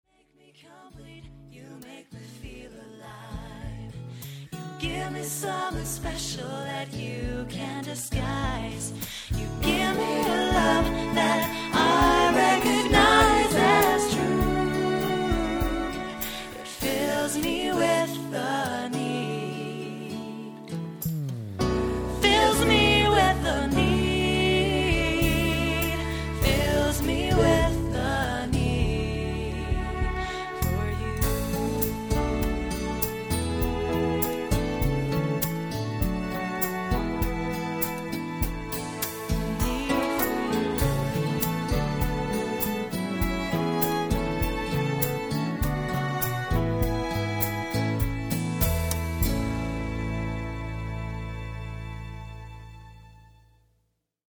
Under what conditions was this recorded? Tracked and mixed on my PARIS rig here.